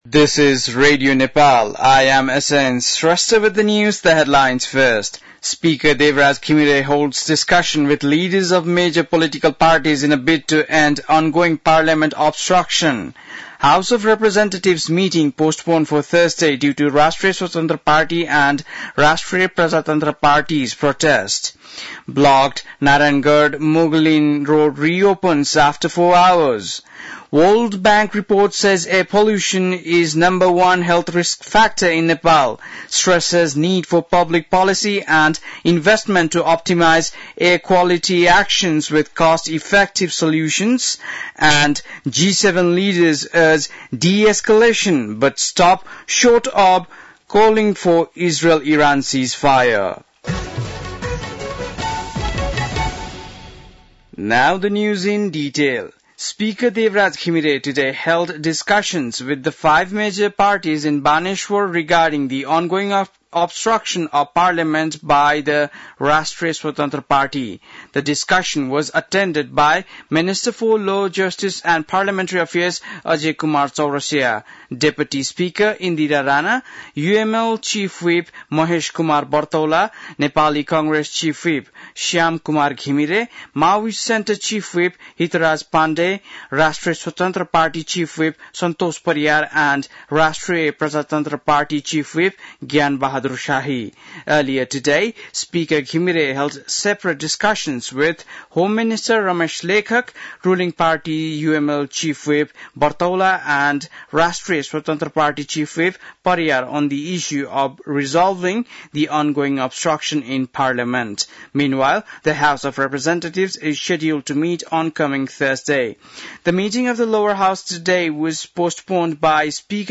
बेलुकी ८ बजेको अङ्ग्रेजी समाचार : ३ असार , २०८२